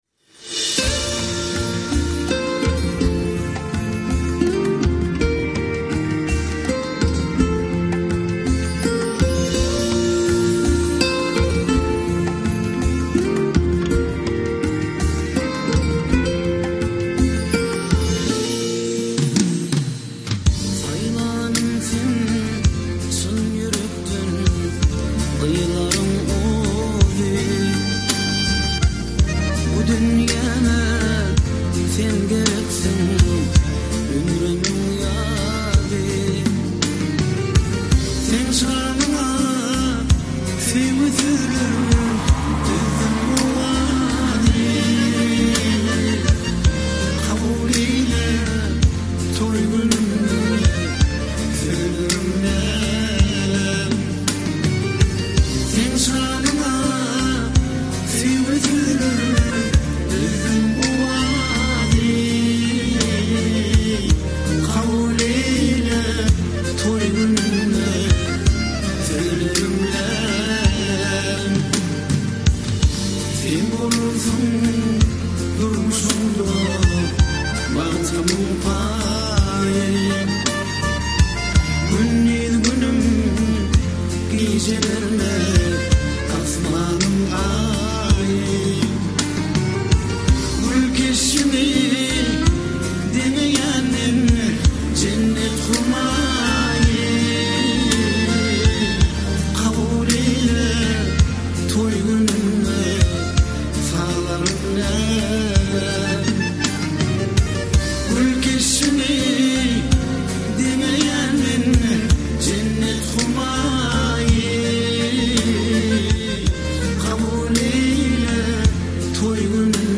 Türkmenistanyň prezidenti Gurbanguly Berdimuhamedowyň ýerine ýetirmegindäki “Saňa meniň ak güllerim” atly aýdym.